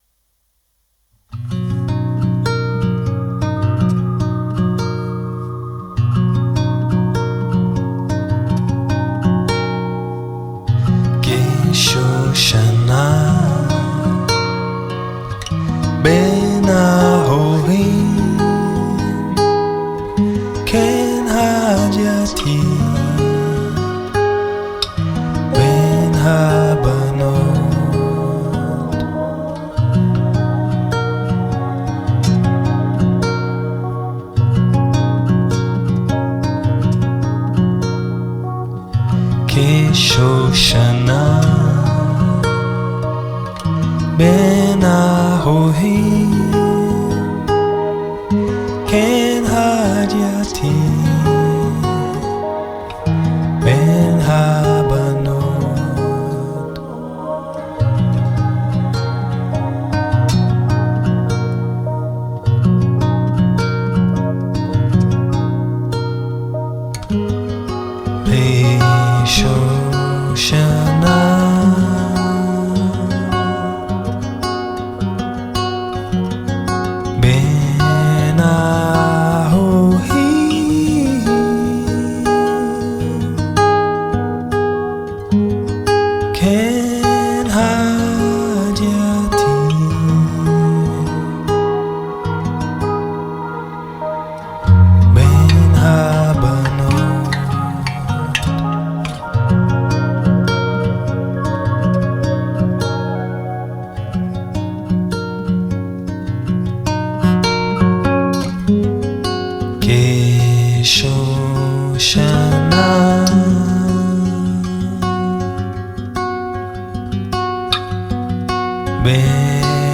Guitars & Vocals
Synths & Backing Vox